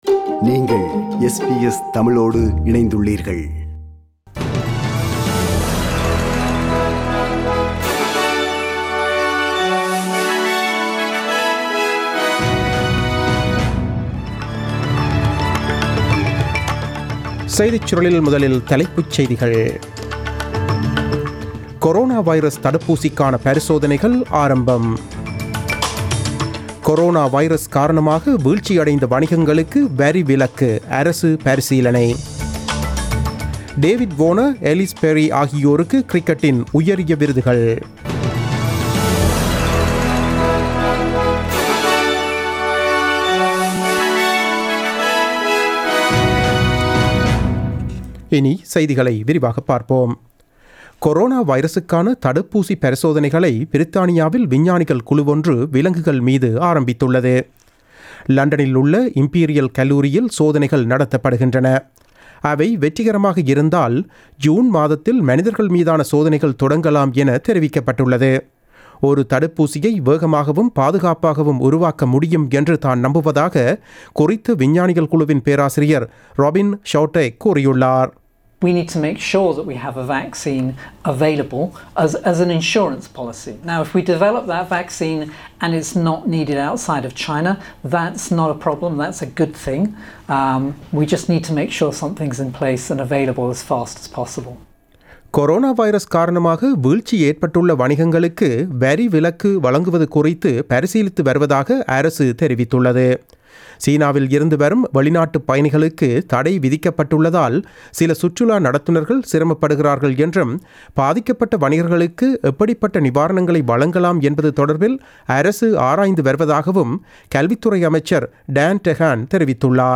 நமது SBS தமிழ் ஒலிபரப்பில் இன்று புதன்கிழமை (12 February 2020) இரவு 8 மணிக்கு ஒலித்த ஆஸ்திரேலியா குறித்த செய்திகள்.